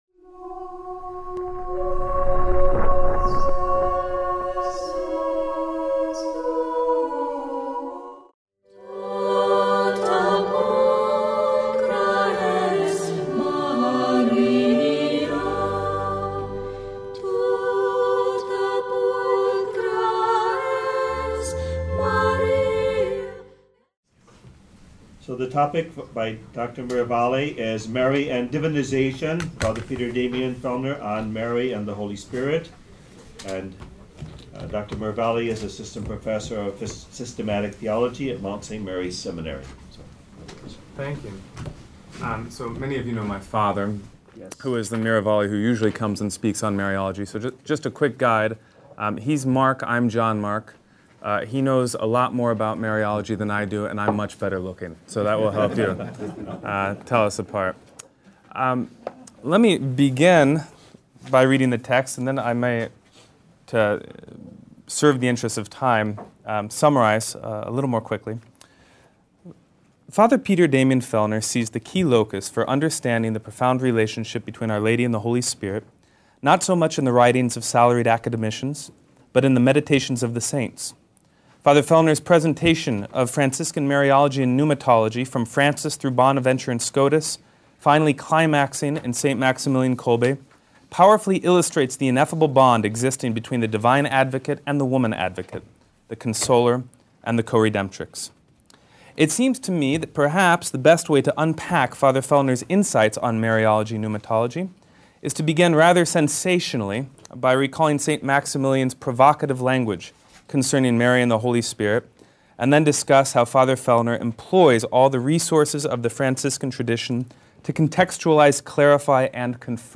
At the Symposium titled "Sursum Actio" at Notre Dame Univ.